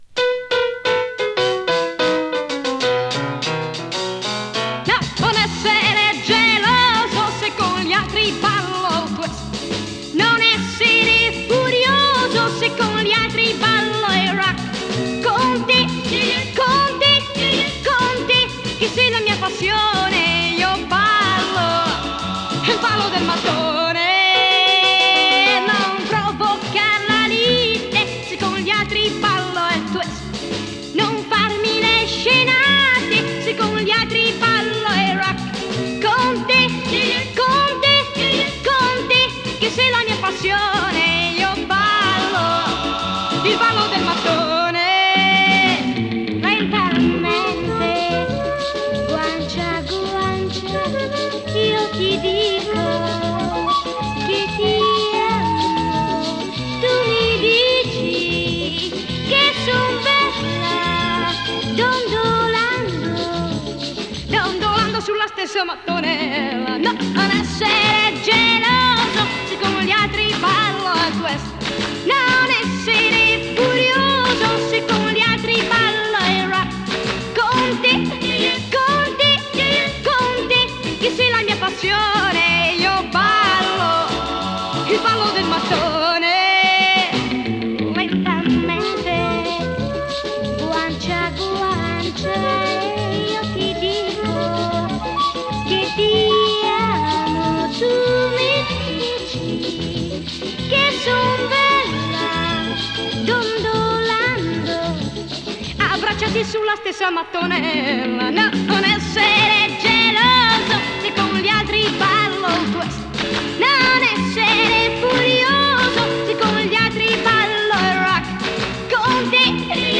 ボサノバ
＊音の薄い部分で軽いチリパチ・ノイズ。
MONO盤です。